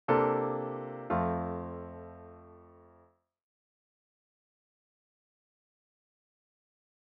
C7 is also a dominant chord of F. So is C9, C11,